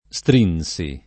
stringere [Str&nJere] v.; stringo [Str&jgo], -gi — pass. rem. strinsi [